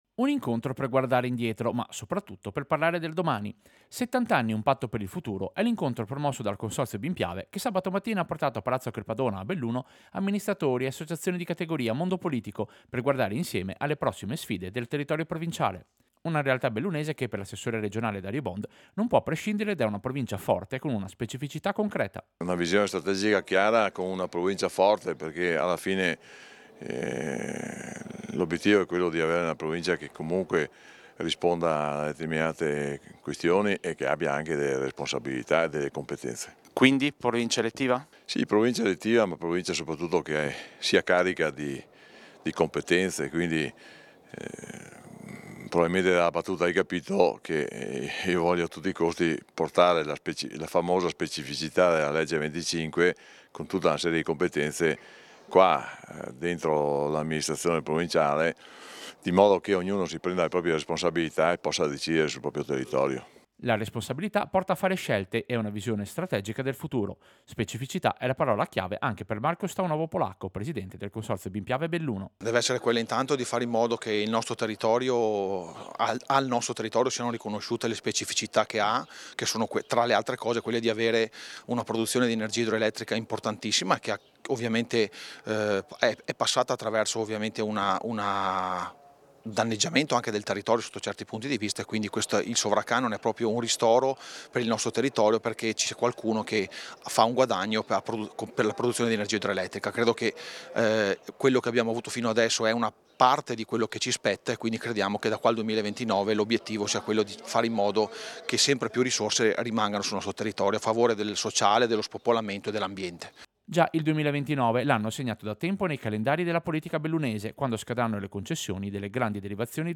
Servizio-Convegno-70-anni-Consorzio-BIM.mp3